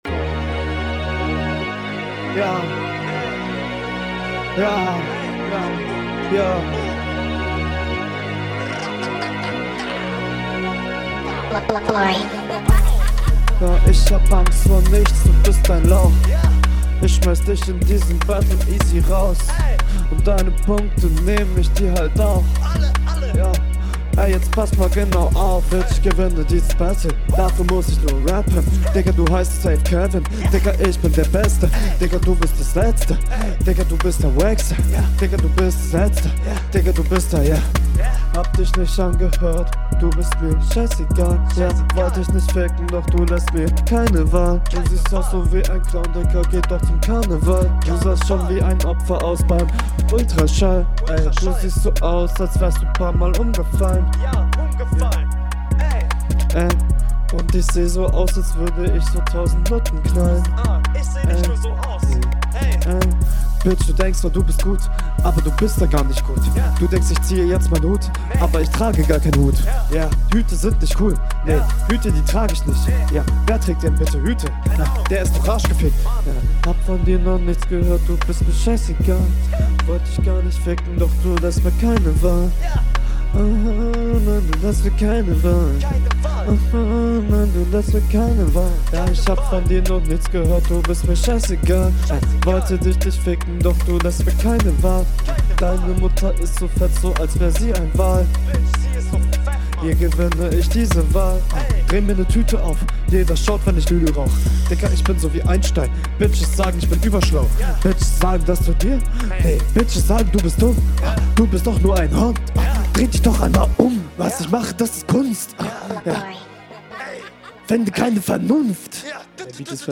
Sehr trappiger flow ^^ Ansatz ist erkennbar; ist alles insgesamt ausbaufähig aber ich wollte dir …